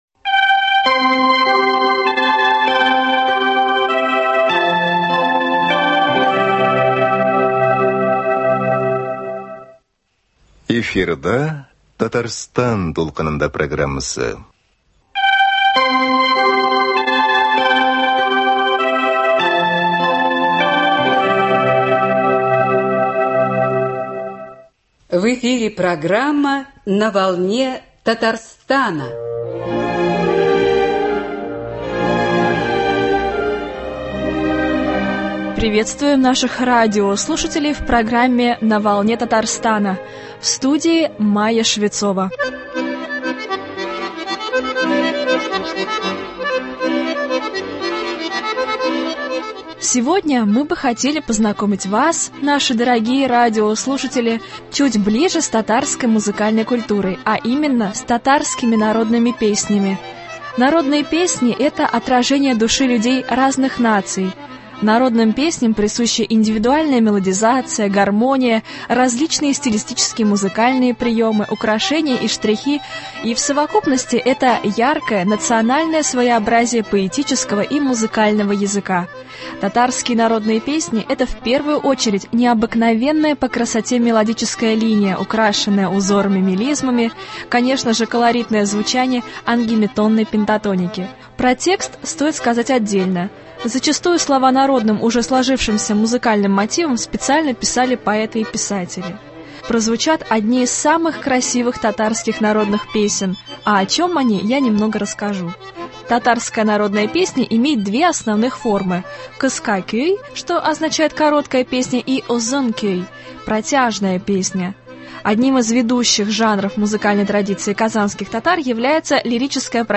Татарские народные песни. Концерт-беседа.